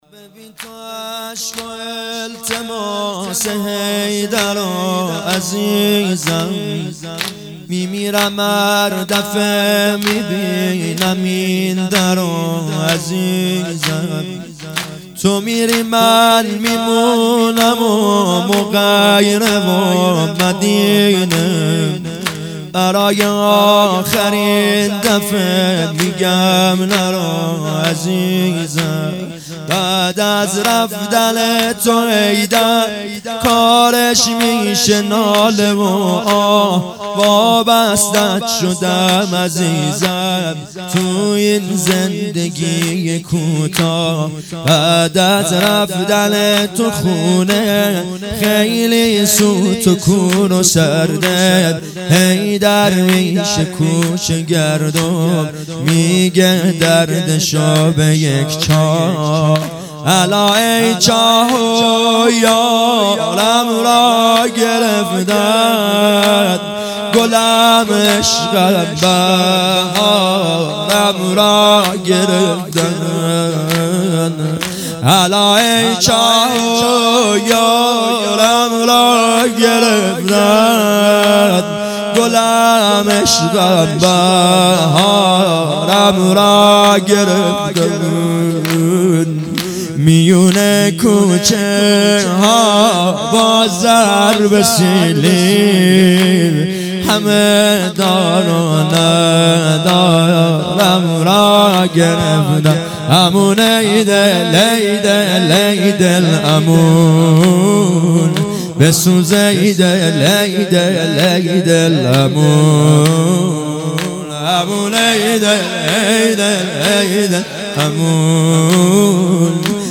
هیئت مکتب الزهرا(س)دارالعباده یزد
فاطمیه دوم_ظهر شهادت